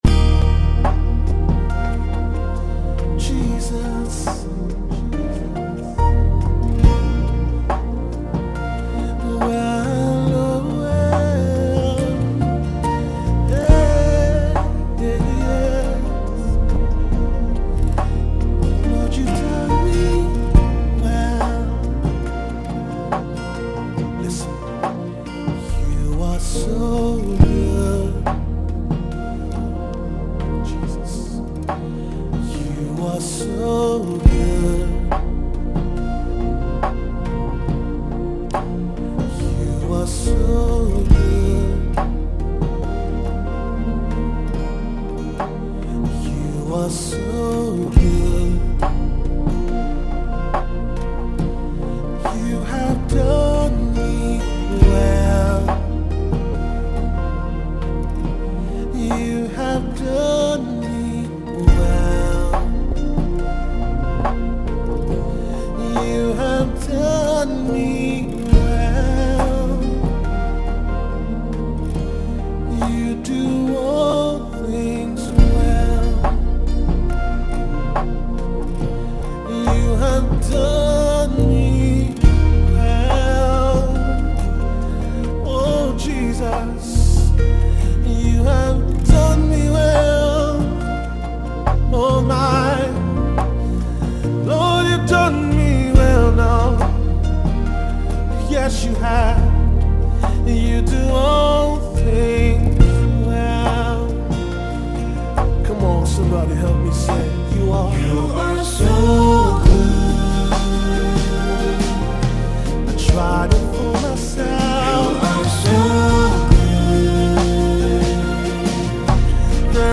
Nigerian gospel song